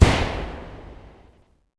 fx_msadpcm_mono_S_FireworkExplosion_01.wav